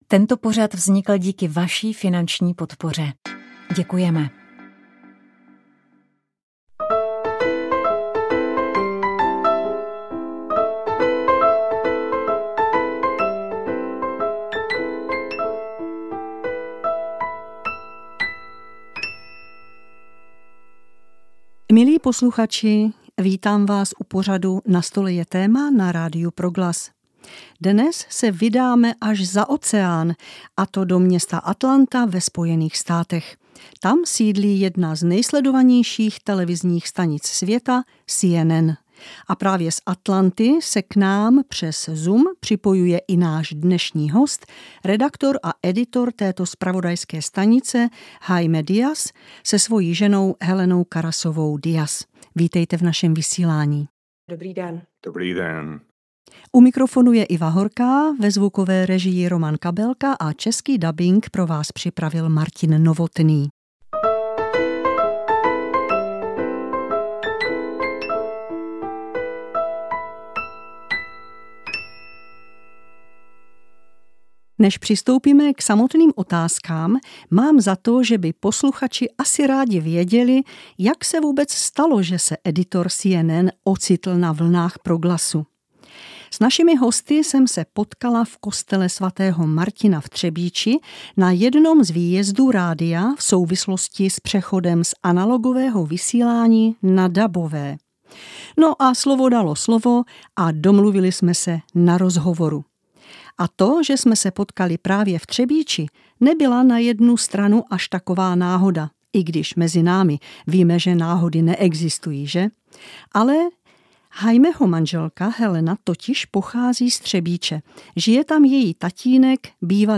Ve čtvrtém dílu cyklu Za zrakem, který se zaměřuje na život se zrakovým hendikepem, se vypravíme na Neviditelnou výstavu, kde se člověk dozví nejen mnoho informací o světě nevidomých lidí, ale může si také na vlastní kůži vyzkoušet pobyt a pohyb v naprosté tmě. Ještě před tím se ale sejdeme k rozhovoru